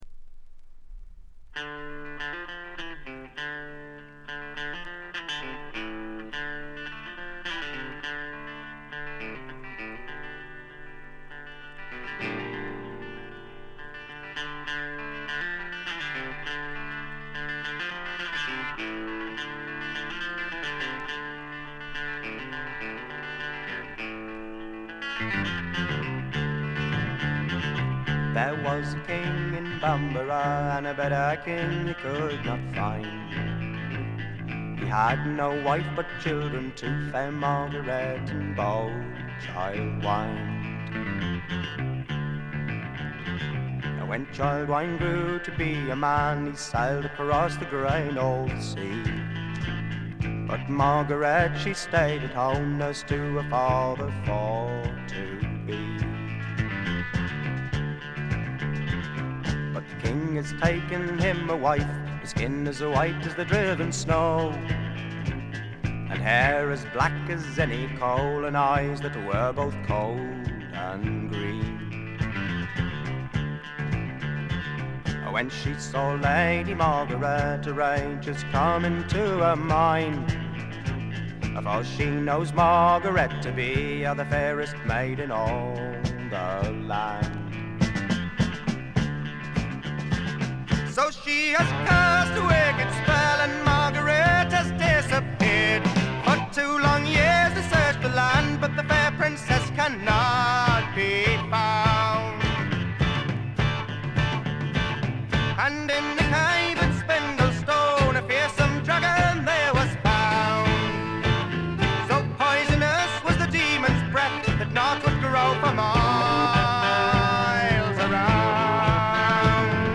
軽微なチリプチ程度。
フォークロックというよりもぐっとフェアポート寄りのエレクトリック・フォークを展開しています。
試聴曲は現品からの取り込み音源です。
Guitar, Mandolin, Harmonium, Bouzouki, Vocals
Drums, Vocals
Electric Bass, Acoustic Bass, Vocals
Violin, Guitar, Mandolin, Whistle, Vocals
Vocals, Guitar, Banjo, Mandolin